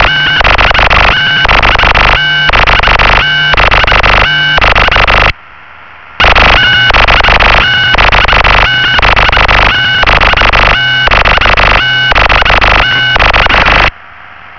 315 Кб 27.05.2005 23:50 Опознайте плиз сигнал.